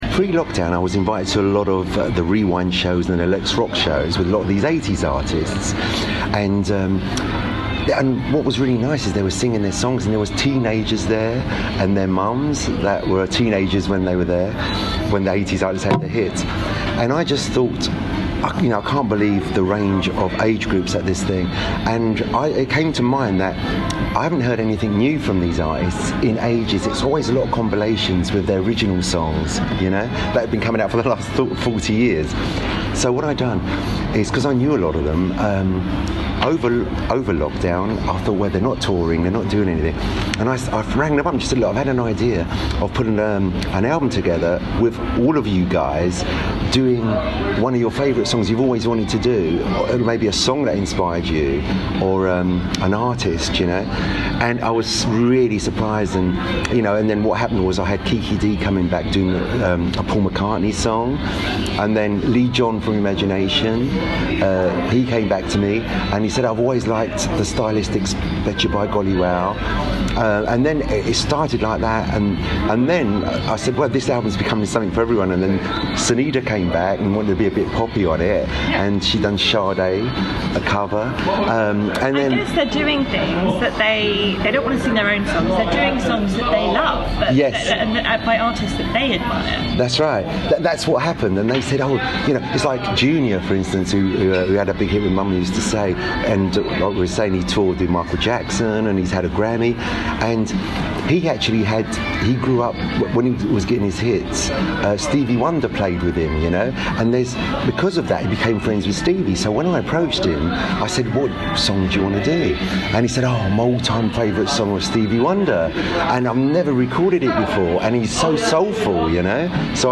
Producer and singer